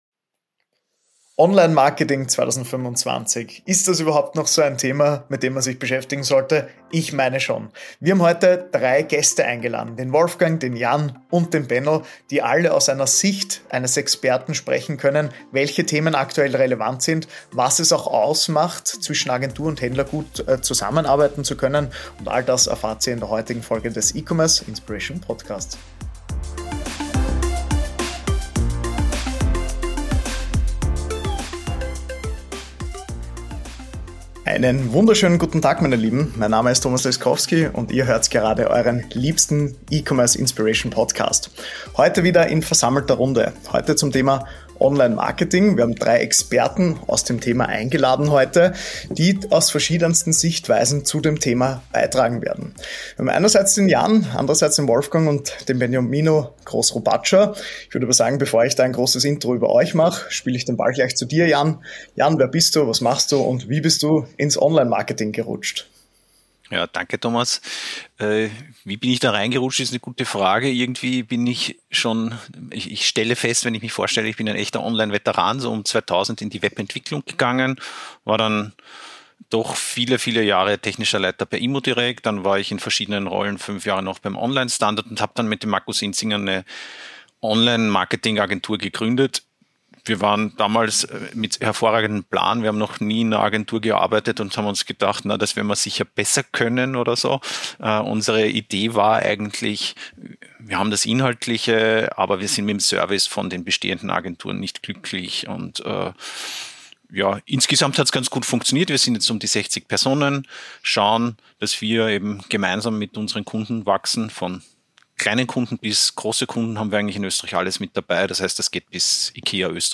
In dieser Diskussion werden die aktuellen Herausforderungen und Fehler im Online-Marketing beleuchtet. Die Teilnehmer betonen die Wichtigkeit von Geduld und kontinuierlicher Verbesserung, anstatt schnelle Lösungen zu suchen.